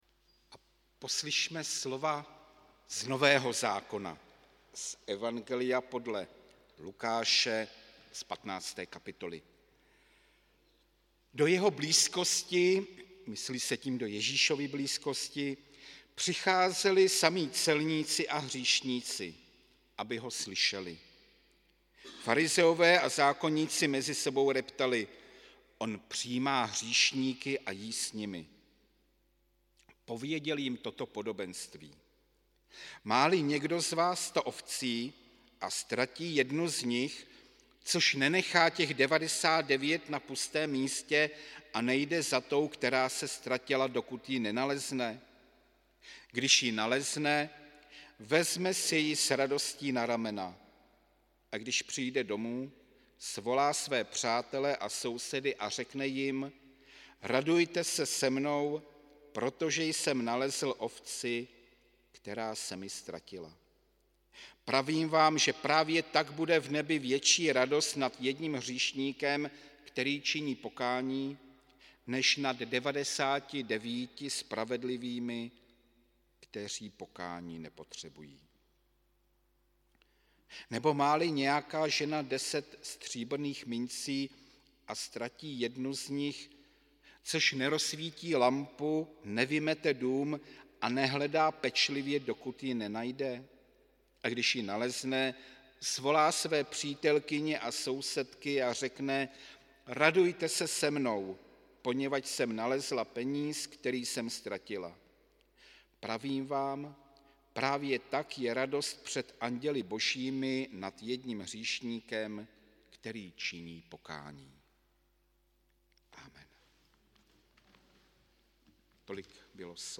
Pátá neděle po sv. Trojici 2. července 2023 – bohoslužby se slavením sv. Večeře Páně a volebním sborovým shromážděním
Gratulujeme a těšíme se na další spolupráci. audio kázání zde sborová ohlášení zde